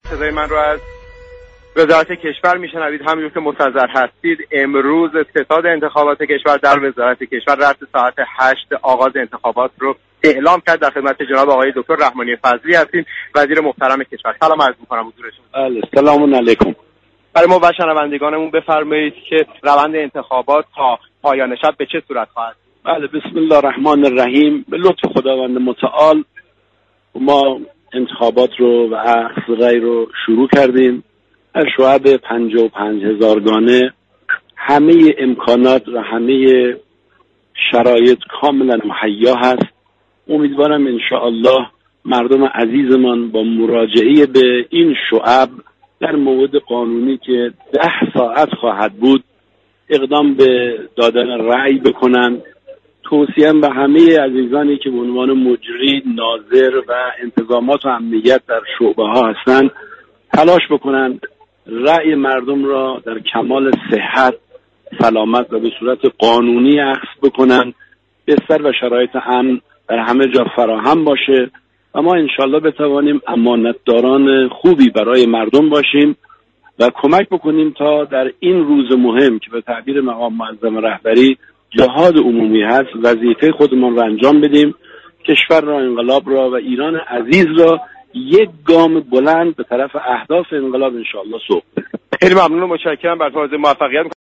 «عبدالرضا رحمانی فضلی« وزیر كشور به رادیو ایران گفت:مسئولان شعبه ها اعم از ناظران، مجریان و انتظامات تلاش كنند رای مردم را در كمال صحت و سلامت و به شكلی قانونی دریافت كنند.